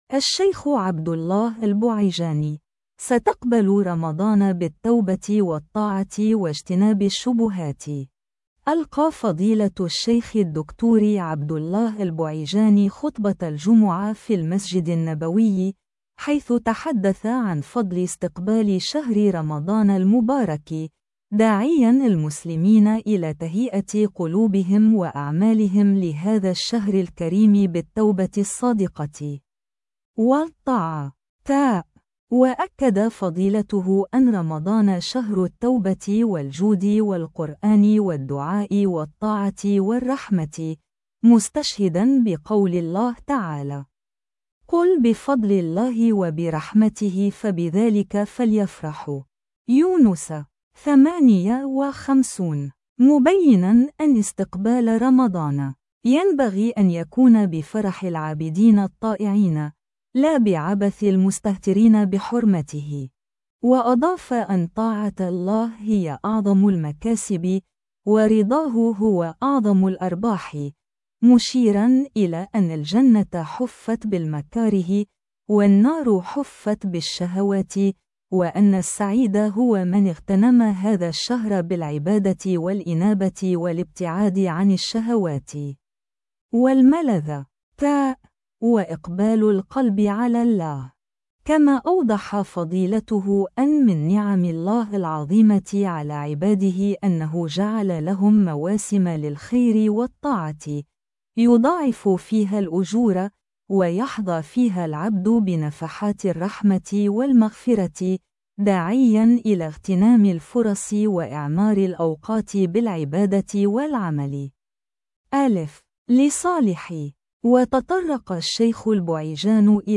ألقى فضيلة الشيخ الدكتور عبد الله البعيجان خطبة الجمعة في المسجد النبوي، حيث تحدث عن فضل استقبال شهر رمضان المبارك، داعيًا المسلمين إلى تهيئة قلوبهم وأعمالهم لهذا الشهر الكريم بالتوبة الصادقة والطاعات.